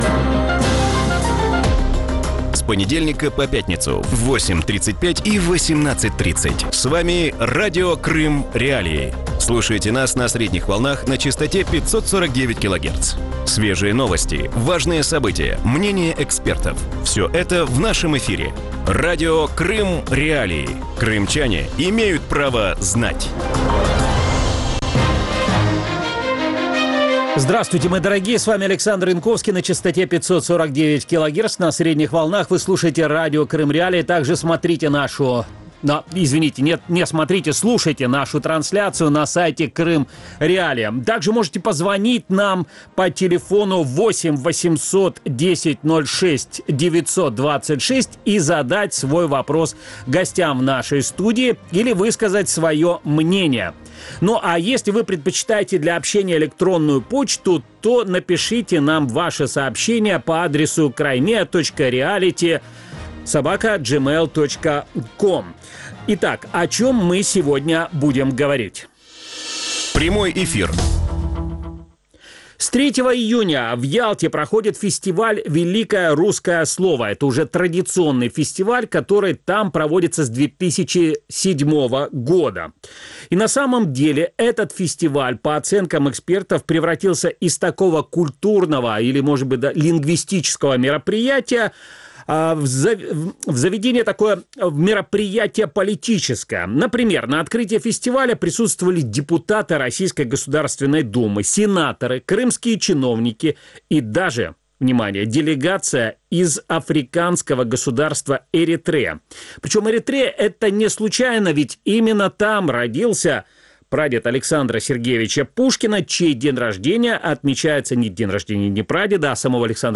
В вечернем эфире Радио Крым.Реалии обсуждают, что стоит за российской культурной политикой и почему некогда лояльные России государства буквально шарахаются от идеи «русского мира».